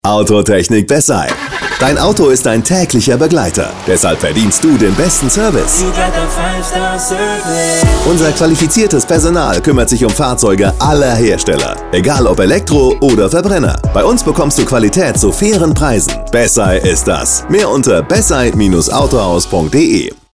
Radiowerbung